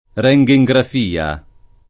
rHntgejgraf&a o